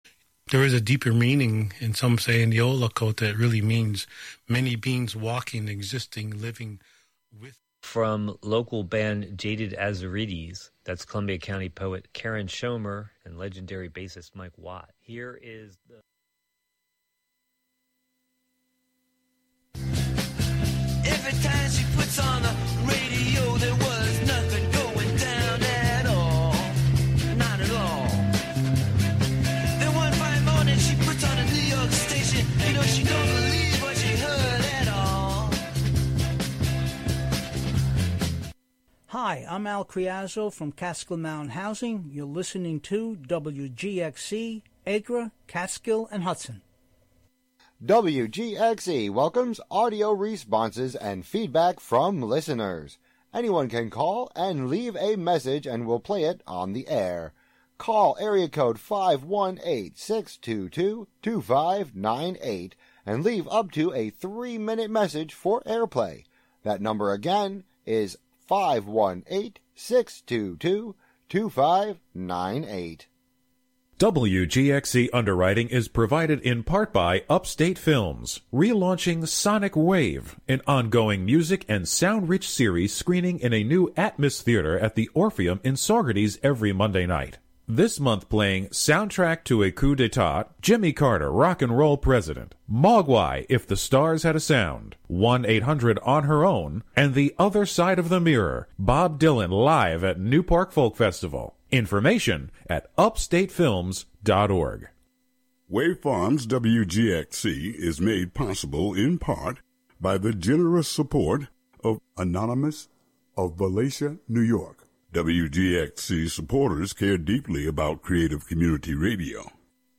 Catskill studio